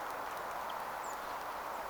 ilmeisesti sinitiaisen
sellainen hiukan pieppomainen ääni
ilm_sinitiaisen_sellainen_vahan_peippomainen_aani.mp3